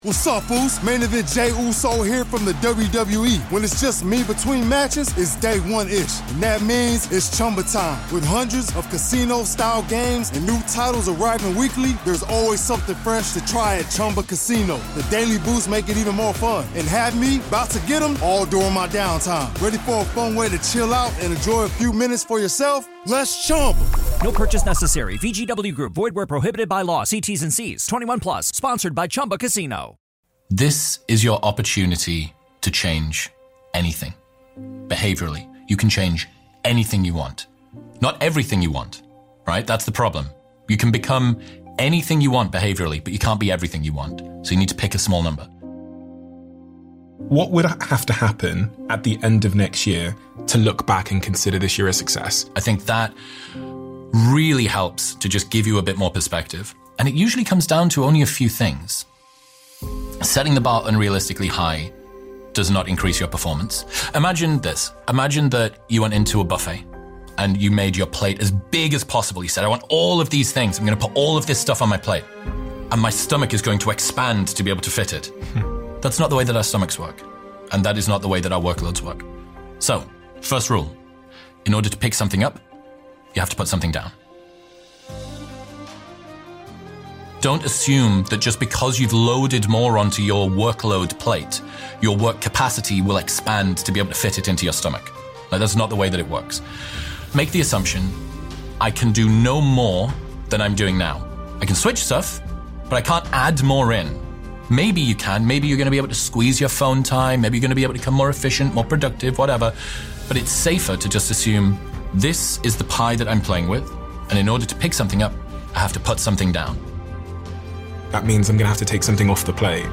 Advice and wisdom from the host of Modern Wisdom. One of the Best Motivational Speeches Featuring Chris Williamson.